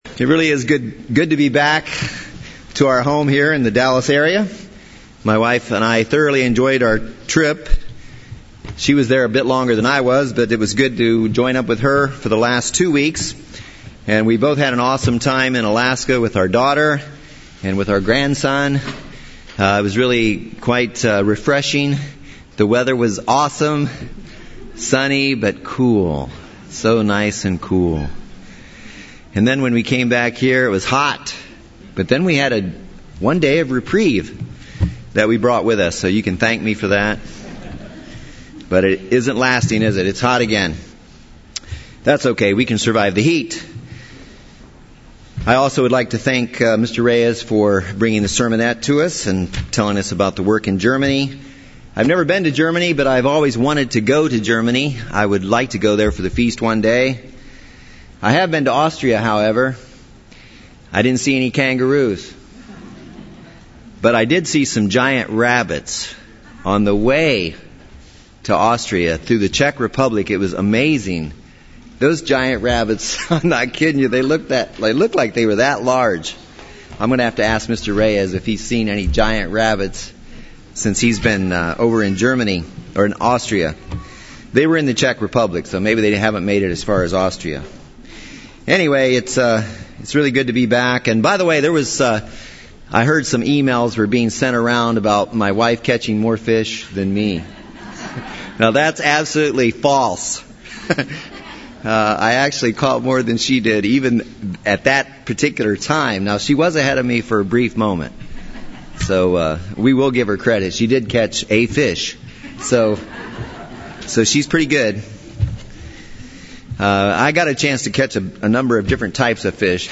(Transcript is for message delivered in Northwest Arkansas on July 13, 2013)